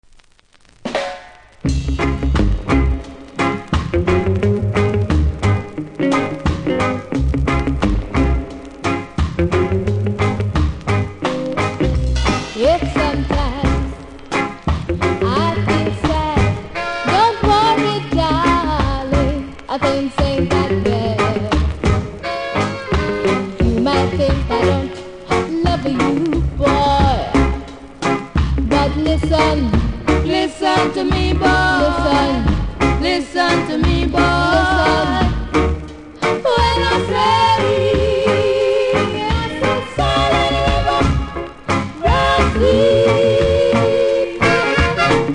ラベルの見た目悪いですが音は良好なので試聴で確認下さい。